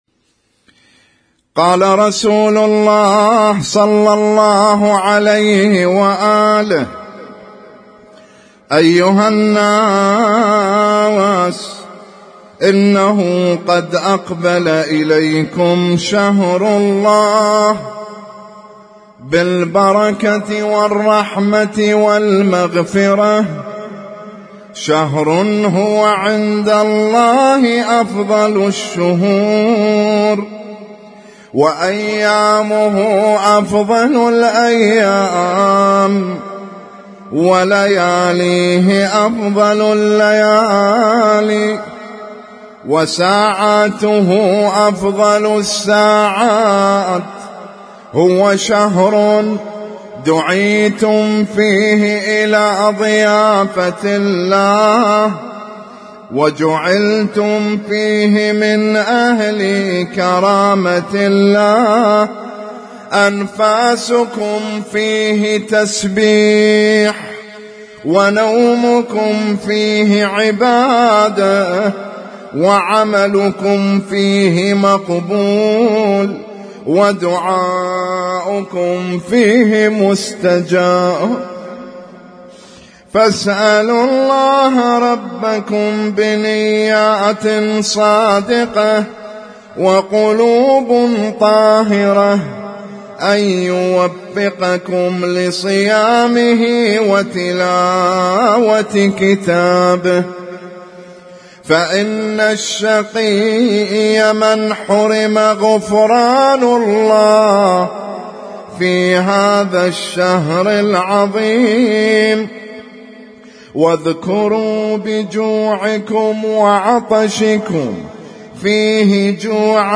خطبة النبي الأعظم في استقبال شهر رمضان المبارك